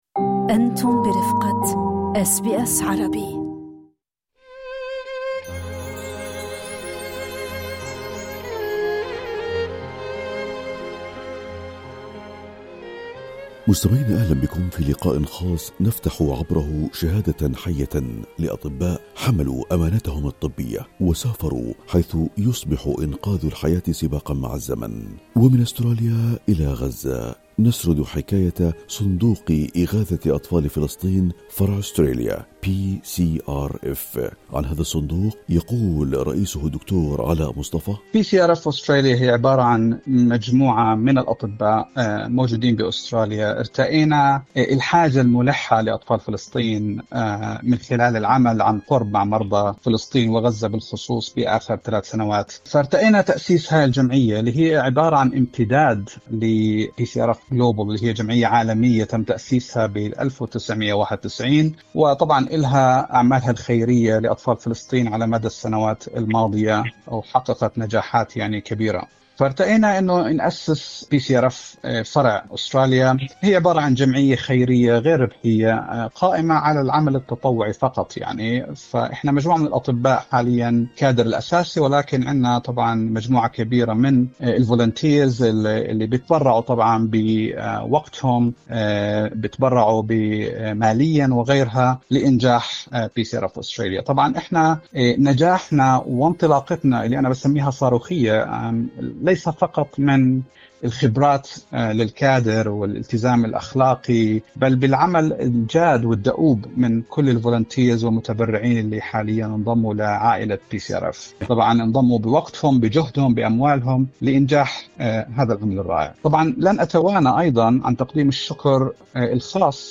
هذا لقاء خاص نفتح عبره شهادة حيّة لأطباء، حملوا أمانتهم الطبية متطوعين، وسافروا إلى الأراضي المنكوبة حيث يصبح إنقاذ الحياة سباقاً مع الزمن. ومن أستراليا، إلى غزة، نسرد الحكاية، عبر صندوق إغاثة أطفال فلسطين فرع أستراليا PCRF Australia.